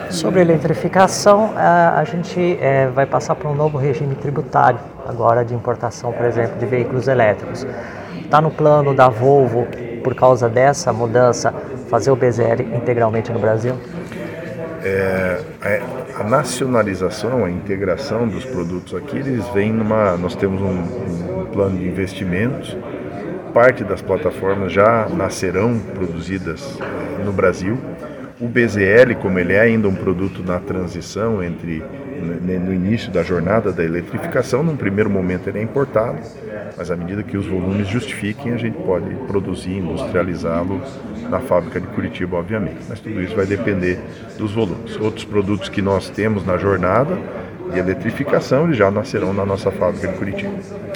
durante entrevista coletiva nesta quarta-feira